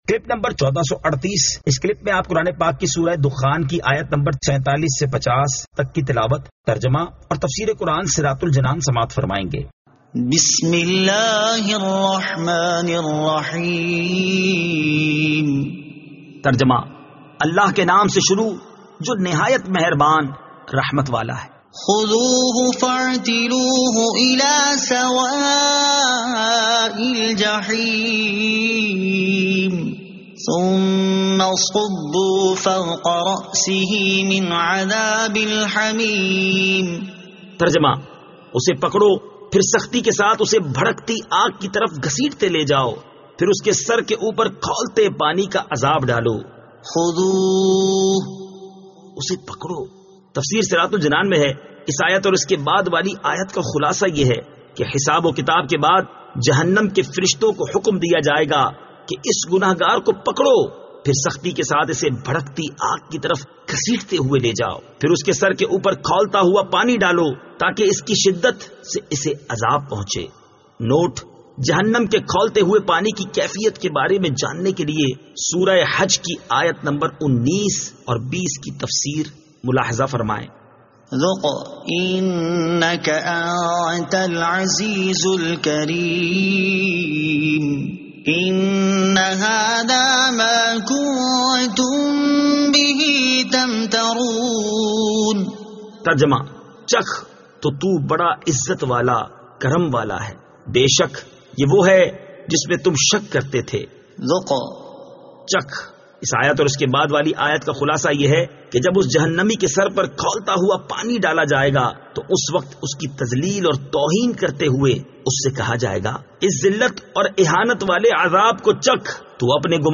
Surah Ad-Dukhan 47 To 50 Tilawat , Tarjama , Tafseer